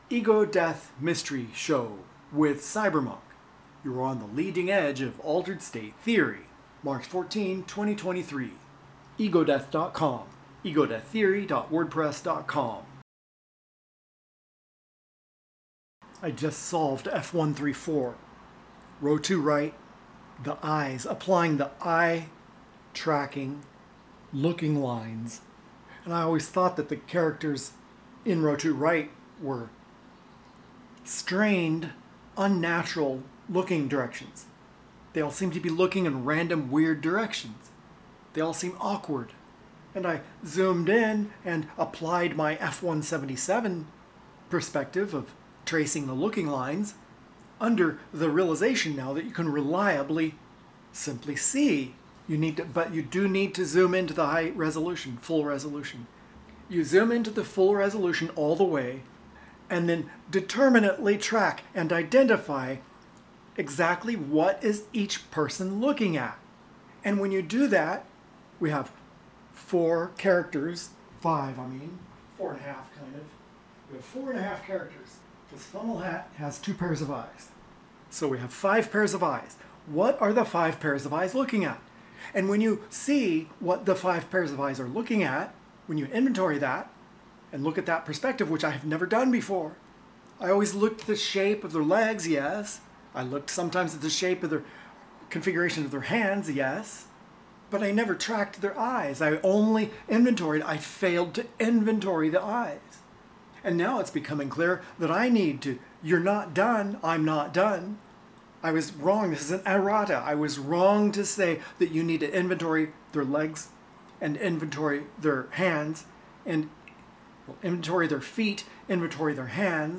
The Egodeath Mystery Show Is Spoken Theory-Development Sessions
78 MB, stereo miking, no music